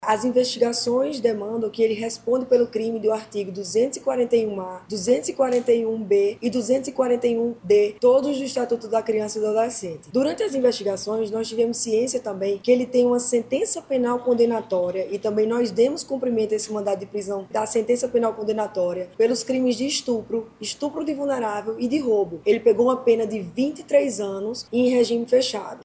A delegada destaca, ainda, a extensa ficha criminal do autor, na prática de crimes sexuais contra crianças e adolescentes.